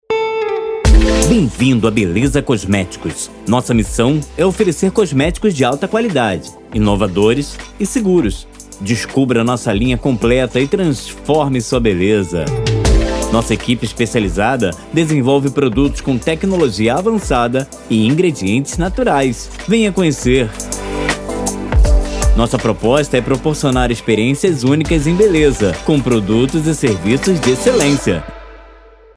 Todos os áudios são produzidos e renderizados na mais alta qualidade e convertidos para o formato que melhor atender suas necessidades.